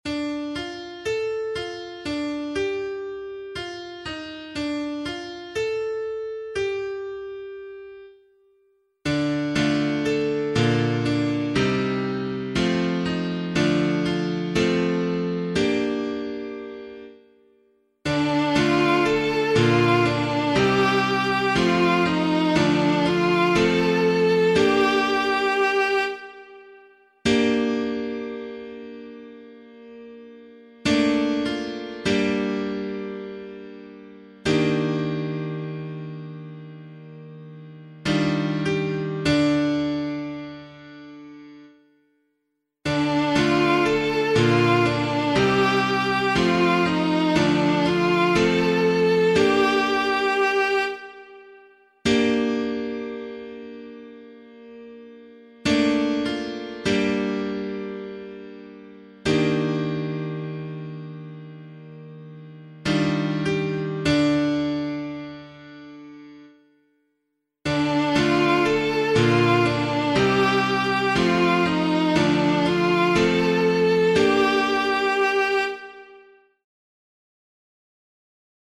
176 Patrick Psalm [APC - LiturgyShare + Meinrad 4] - piano.mp3